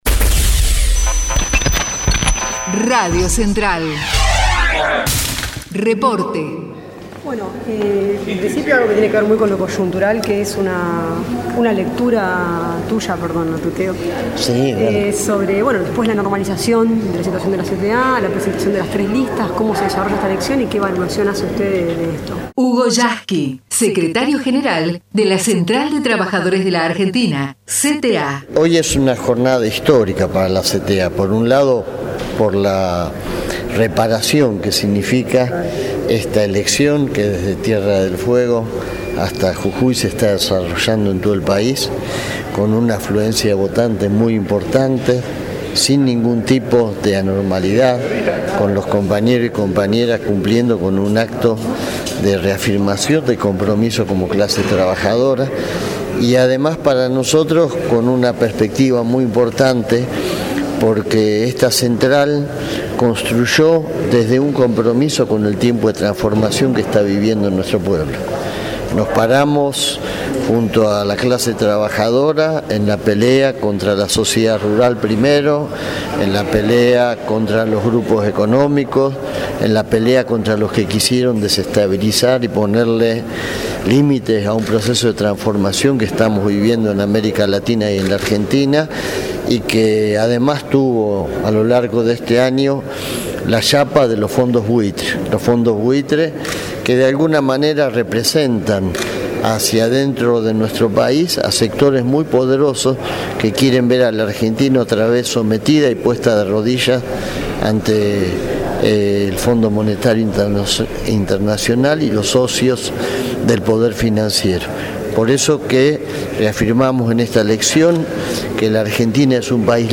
HUGO YASKY (entrevista)